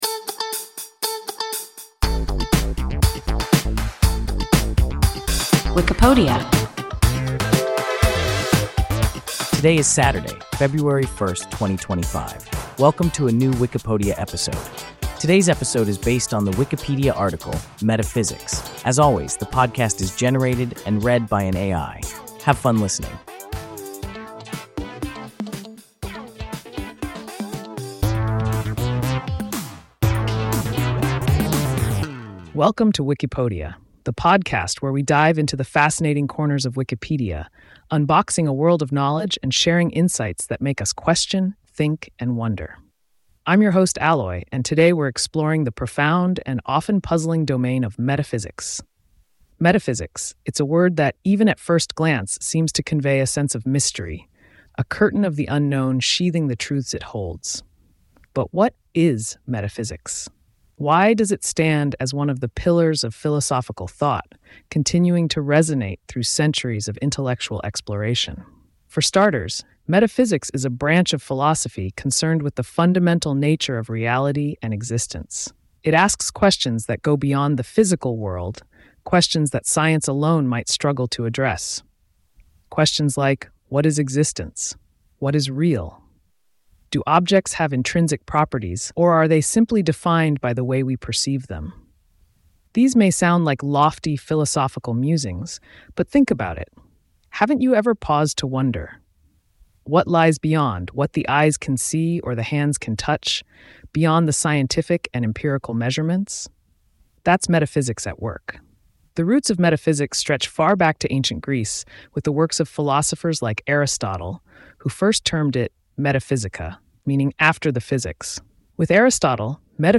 Metaphysics – WIKIPODIA – ein KI Podcast